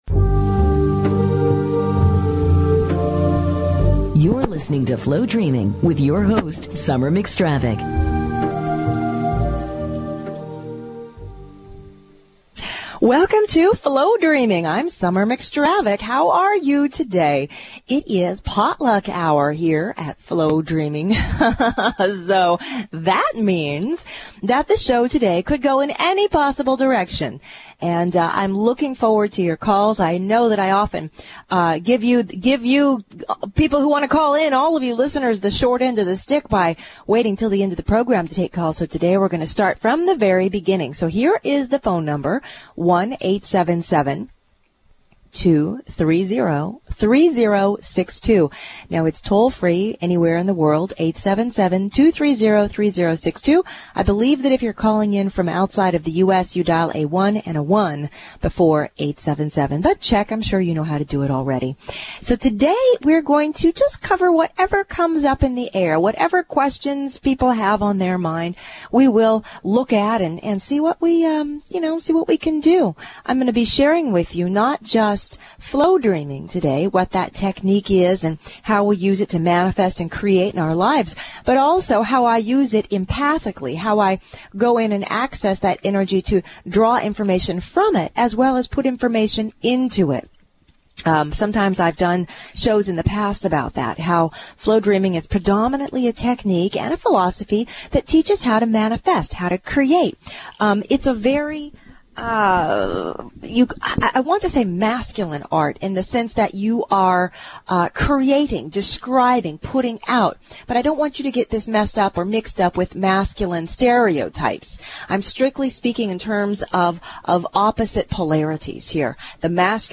Talk Show Episode, Audio Podcast, Flowdreaming and Courtesy of BBS Radio on , show guests , about , categorized as
Flowdreaming is a fun, fast-paced show about manifesting and Flow energy.